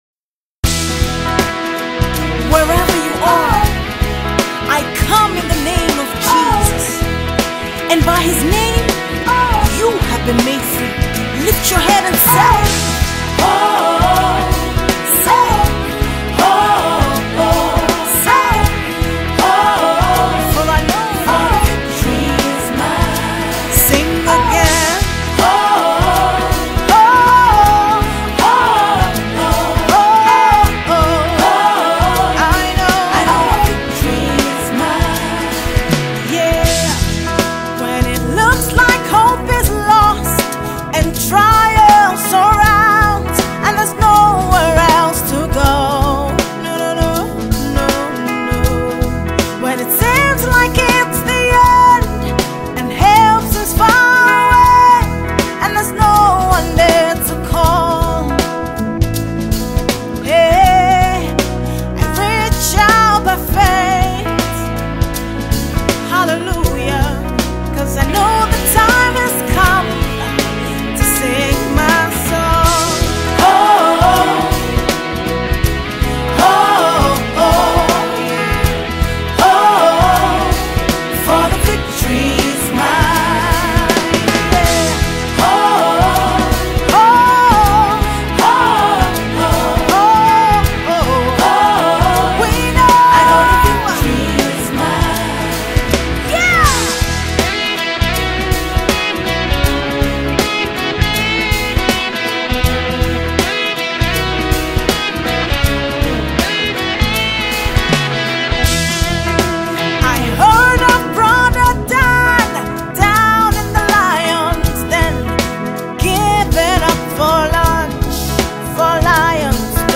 soul-lifting gospel song
” a soul-stirring gospel anthem from
Genre:Gospel